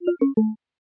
critical_lowbattery.ogg